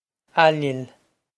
Ailill A-lil
A-lil
This is an approximate phonetic pronunciation of the phrase.